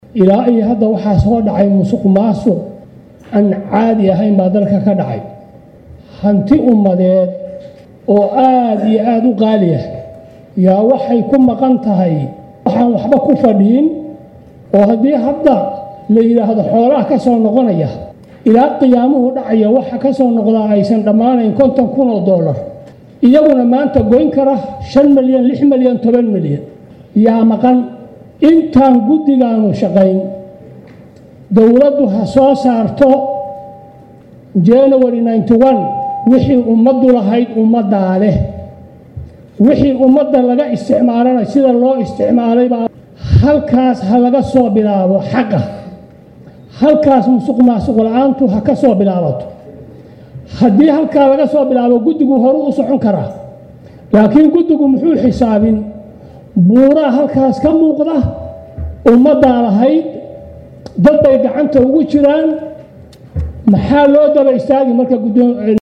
Hoos Ka Dhageyso Codka Sanoter Cabdi Waaxid Cilmi Goonjeex.